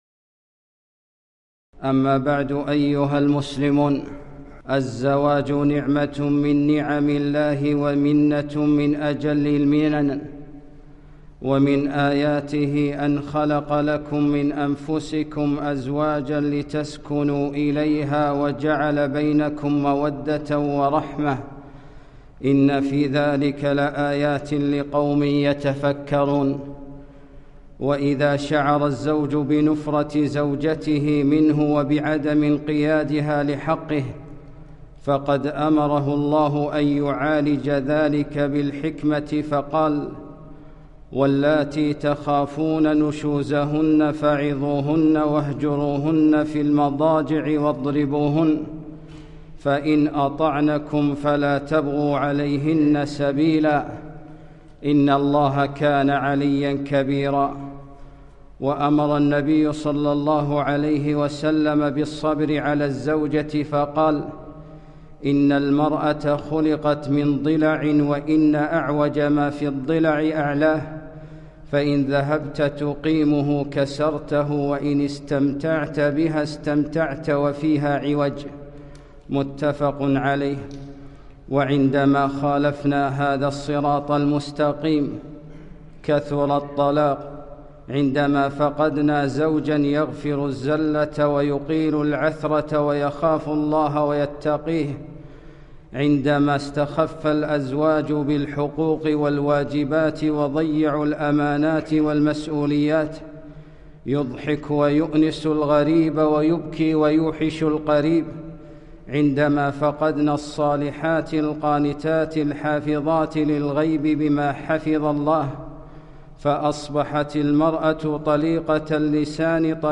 خطبة - أسباب كثرة الطلاق